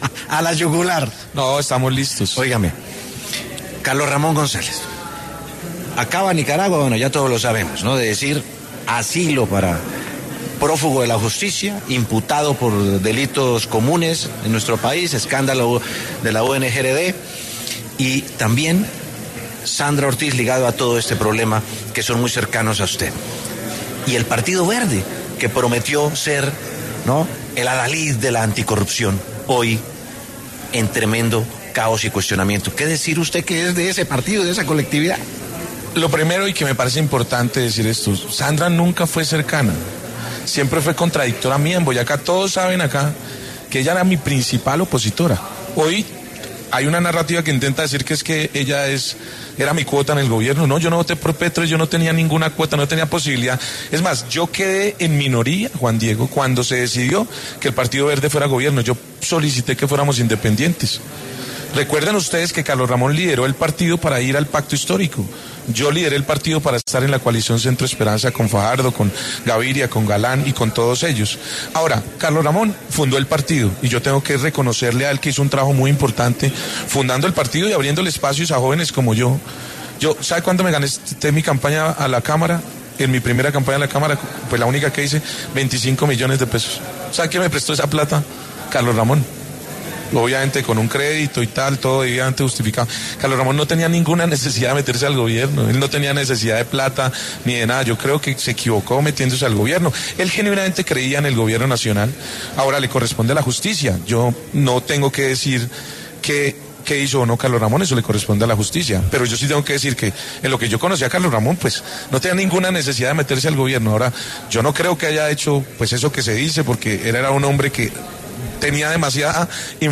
El gobernador de Boyacá, Carlos Amaya, habló en La W sobre el proceso que lleva en contra Carlos Ramón González y defendió al partido Alianza Verde.